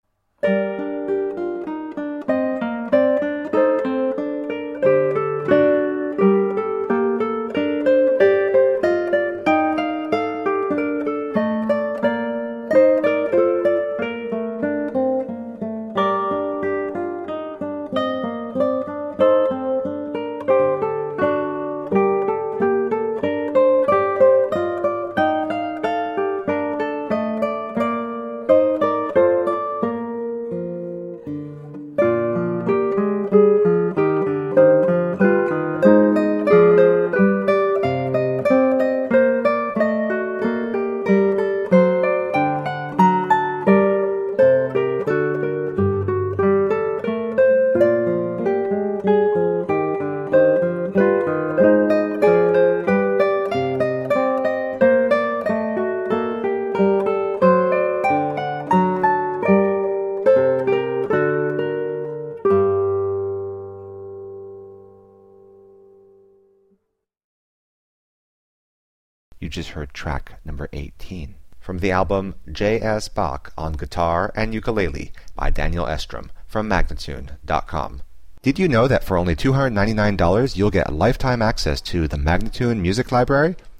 Classical, Baroque, Instrumental
Classical Guitar, Ukulele